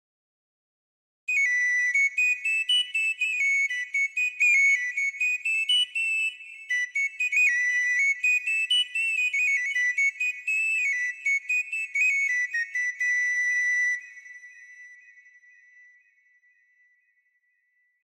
Sono partito da un sample di “ocarina” (strumento tradizionale a fiato usato anche nella musica folk dell'Italia centrale) e, provando una estensione alta della tastiera, ho suonato delle melodie come di sostegno alla melodia vera e propria della voce.
Ocarina.mp3